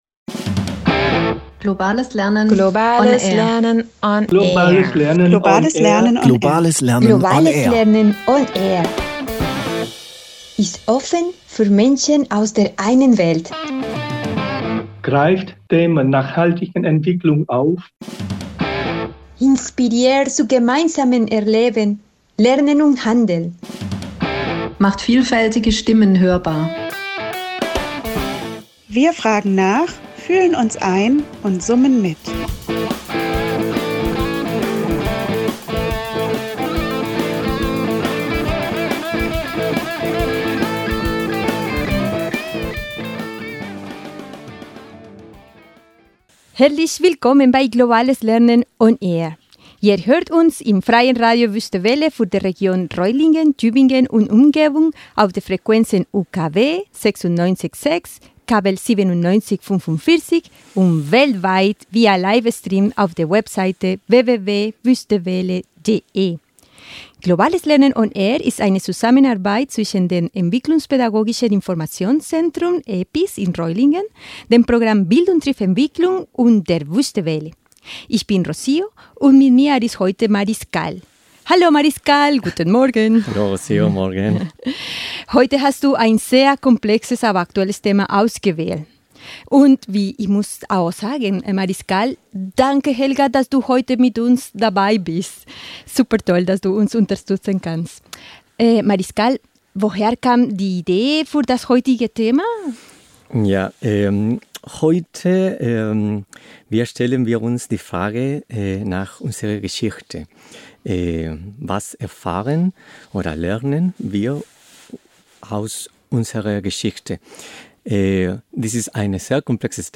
Aus einem persönlichen Ausblick, der die ersten Erinnerungen und das Lernen über das, was wir als unsere Geschichte, die Geschichte unserer Gemeinschaft oder die unserer Länder betrachten, durchläuft, diskutieren und kommentieren unsere BtE-Referenten den Lernprozess, die Reflexionen und die Konsequenzen, die "unsere Geschichte" für unsere Gegenwart und unsere Zukunft hat.